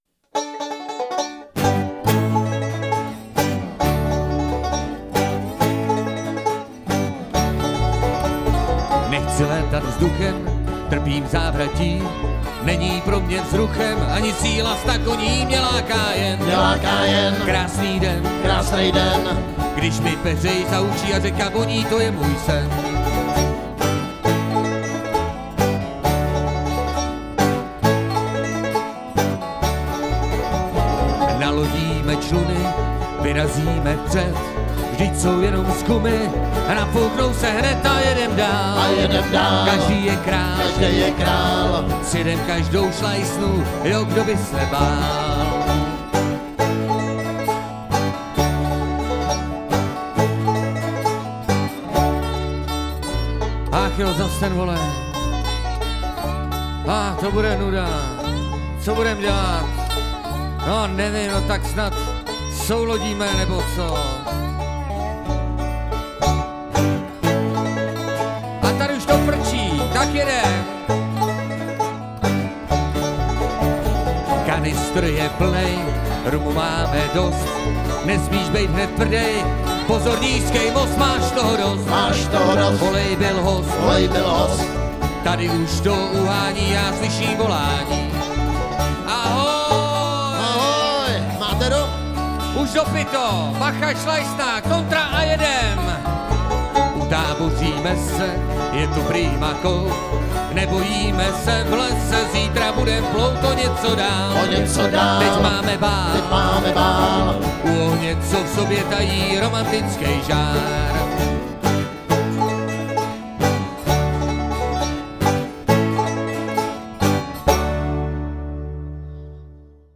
Festival trampských písní